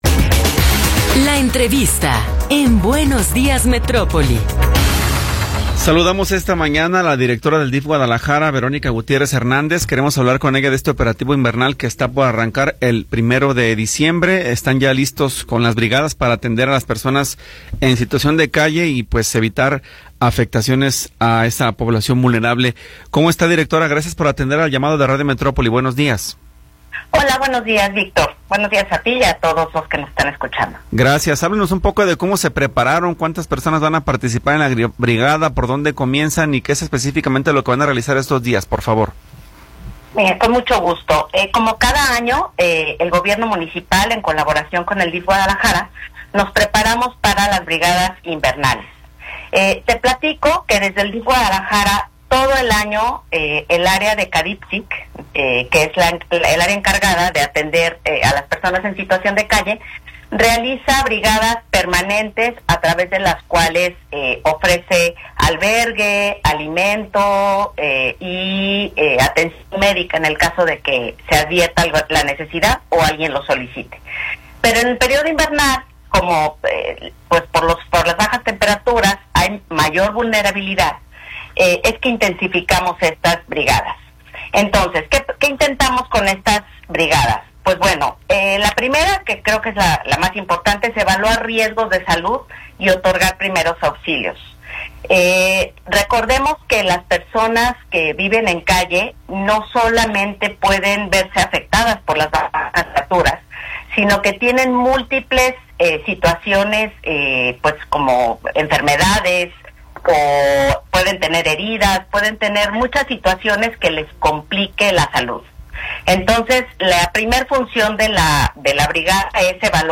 Entrevista con Verónica Gutiérrez Hernández